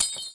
叮叮当当砸玻璃
描述：碎酒杯的碎片被打到一起，发出叮当声。砸碎的效果还不错，它的长度比一些样品要长一些。
Tag: 破碎 玻璃 碎片 碎片 粉碎 粉碎 叮叮当当